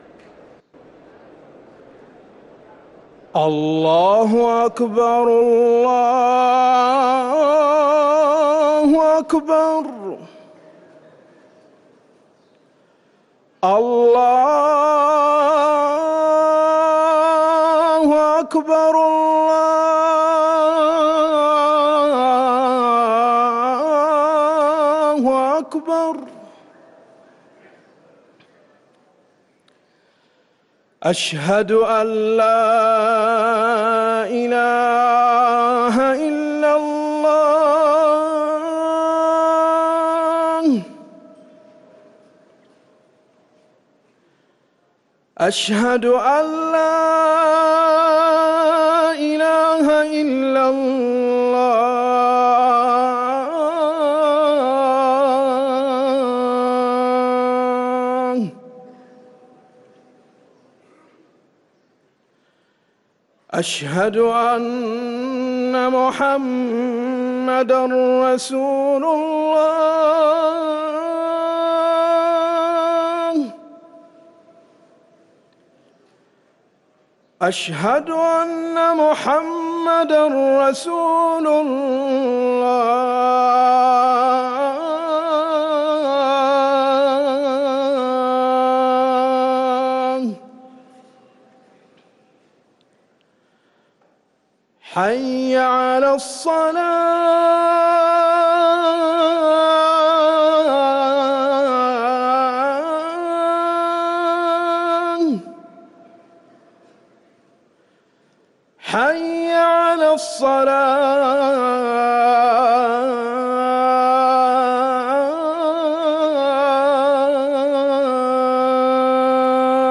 أذان المغرب للمؤذن أحمد الأنصاري الخميس 11 رجب 1444هـ > ١٤٤٤ 🕌 > ركن الأذان 🕌 > المزيد - تلاوات الحرمين